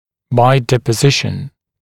[baɪ ˌdepə’zɪʃn] [ˌdiːpə-][бай ˌдэпэ’зишн] [ˌди:пэ-]путем депонирования (о развитии кости)